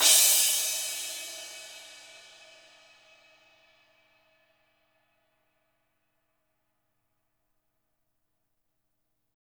Index of /90_sSampleCDs/Spectrasonics - Burning Grooves CD2/Partition H/BG CYMBALS